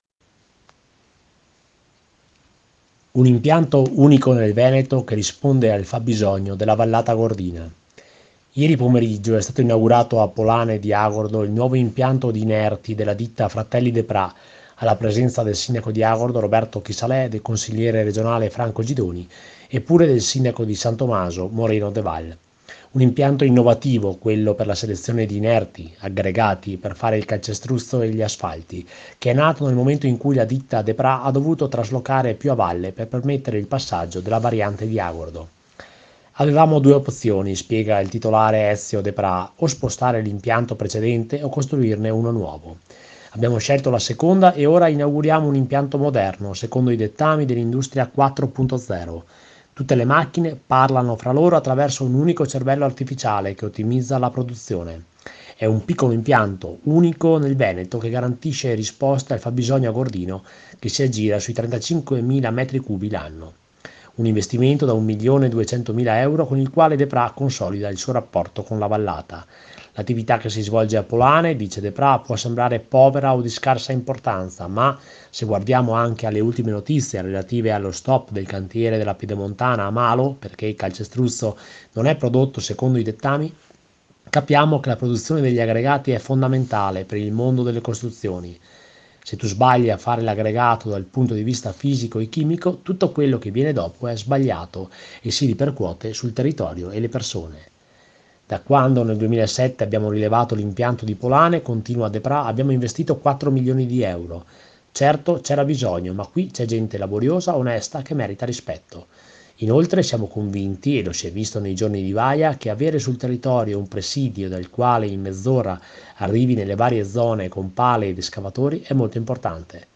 Inaugurazione del nuovo impianto di lavorazione aggregati di Agordo. Piazzale dell’impianto in località Polane, tra lo Stadio Comunale di Agordo e il torrente Cordevole.
NEL VENTO… SEMPRE FASTIDIOSO PER I MICROFONI….